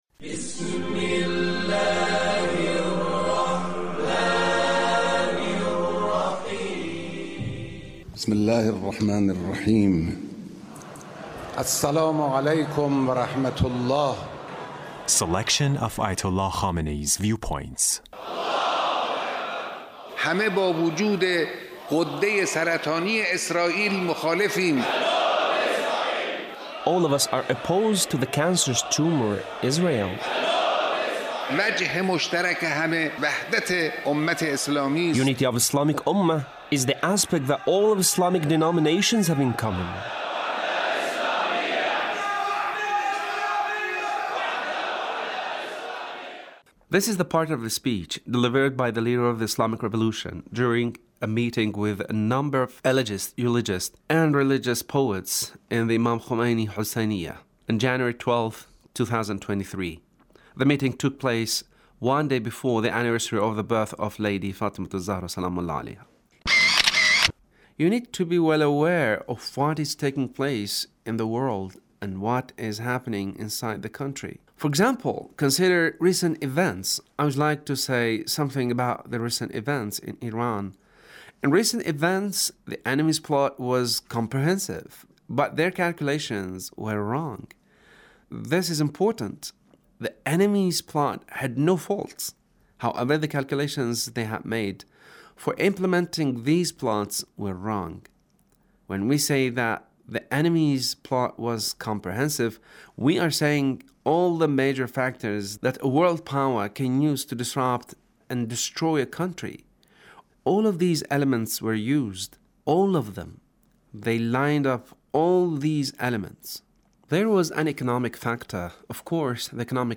Leader's Speech meeting with Eulogists